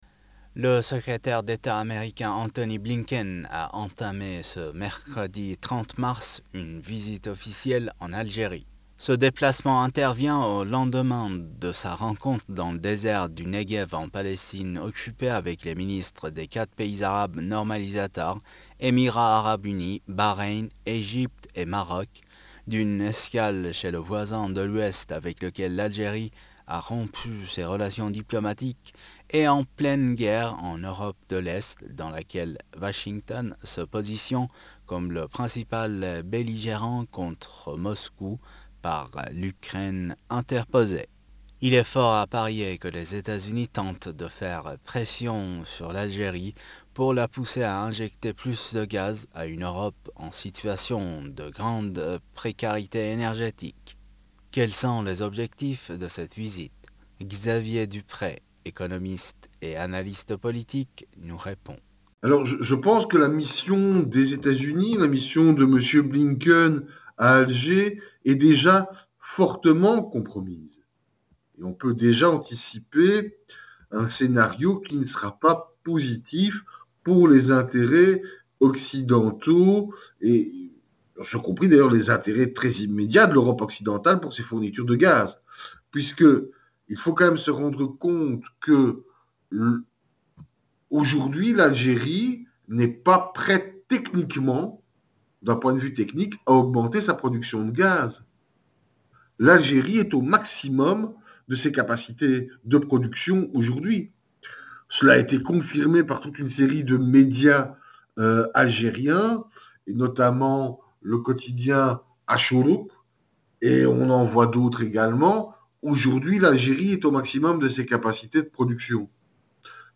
Cliquez ICI & Écoutez l'interview 2RP 2RP, la radio qui révolutionne le regard du monde sur l'Afrique!